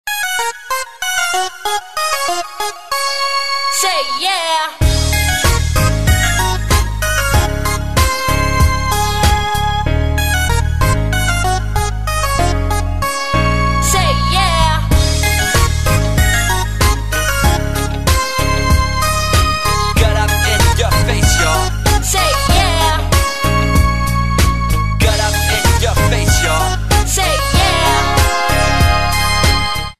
В стиле Хип-Хоп